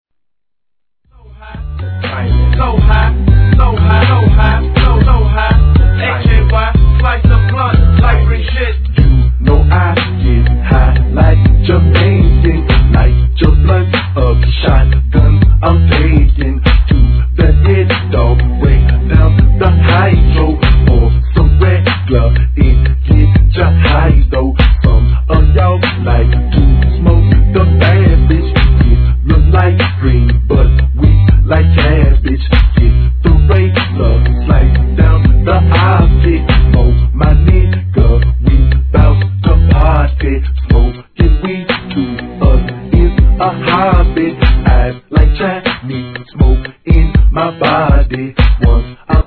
G-RAP/WEST COAST/SOUTH
粘りつくようなドラムパターンに淡々としたシンセがいかにも南部独特の雰囲気をかもし出します。